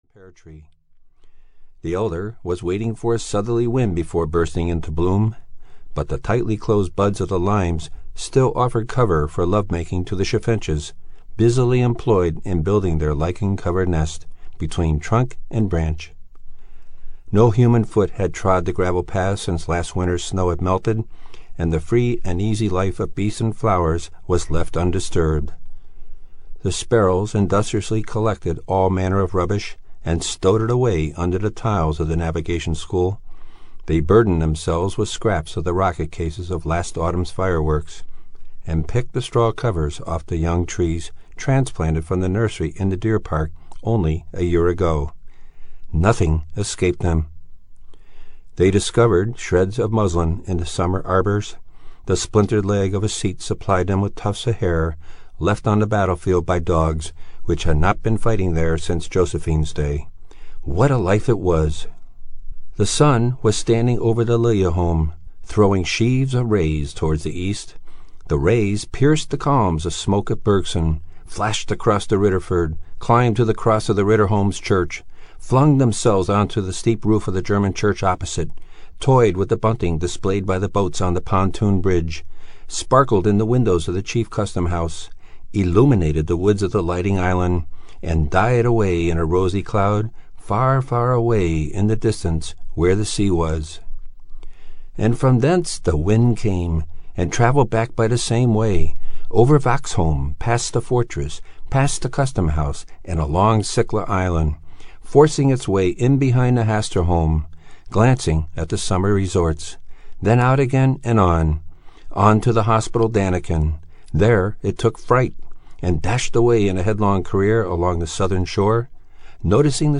The Red Room (EN) audiokniha
Ukázka z knihy